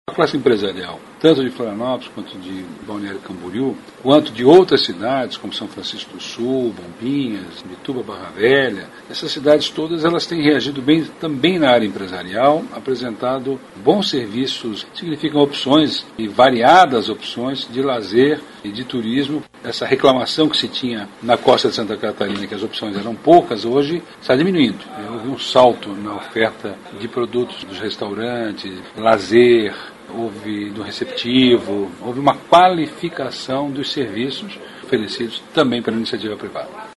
aqui para ouvir comentário do secretário Vinicius Lummertz sobre a qualificação de serviços ligados ao turismo no estado.